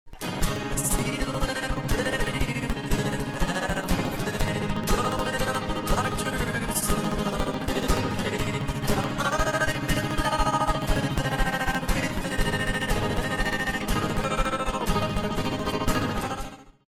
Fast